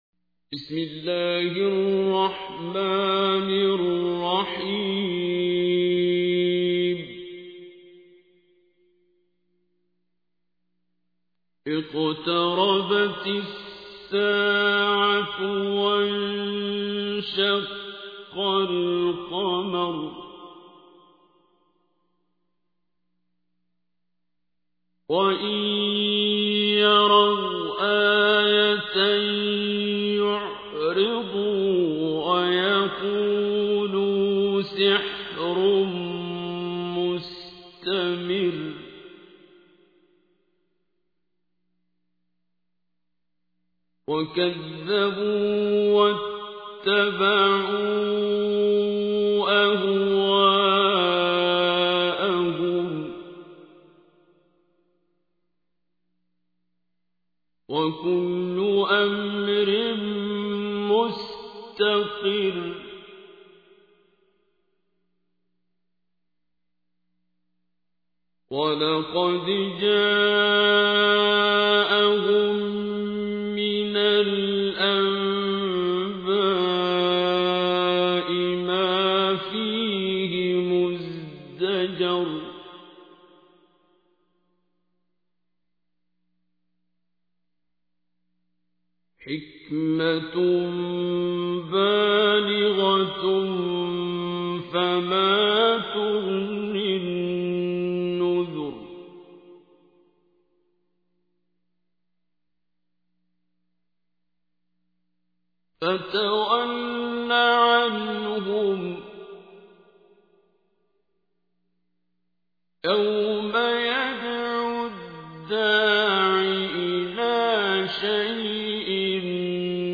تحميل : 54. سورة القمر / القارئ عبد الباسط عبد الصمد / القرآن الكريم / موقع يا حسين